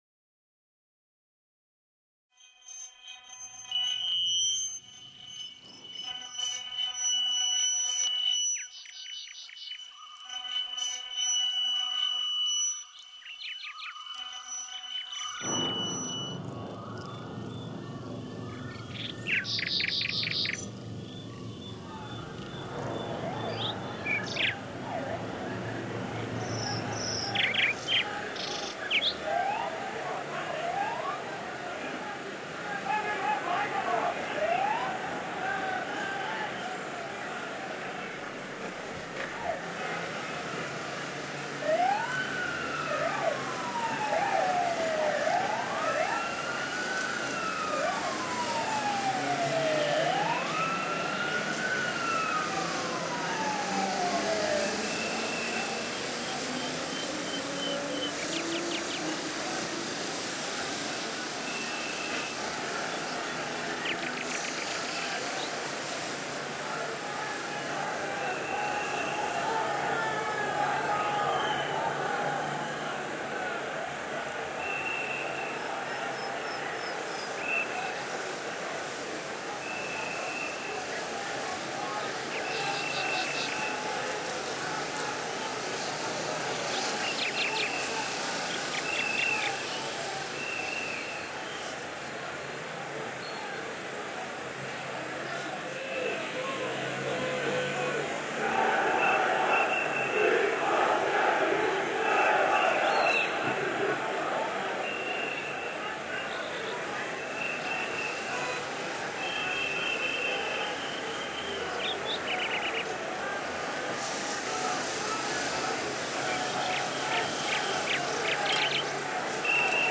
Zvukovi rijeke, ljudi, automobila tvore u zvučnoj panorami neku vrstu kulise zvuka od bijele buke. Na drugoj strani, zvuk ptica, zvuk policijskih zviždaljki, sirena, zvuk kamenja koje udara od metalne ploče, zvuk škripanja automobilskih guma i mnogi drugi zvukovi koji se pretaču stvaraju interesantnu elektro-akustičnu kompoziciju.
Elektro-akustische Klang Komposition das während einer Fußballfans Straßenschlägerei entstanden ist.
Electro-acoustic sound installation created during the football fans street fight, composition of sounds that sound similar, have same physical characteristics but also have opposite meaning and opposite sources, The river, people and cars sound like a white noise in the sound scape, sound of birds, sound of police whistle, sound of stones hitting into the metal plates, sound of car ties… fuse and create an composition.